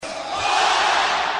DND Weekly What - What? (Crowd)
Category: Radio   Right: Personal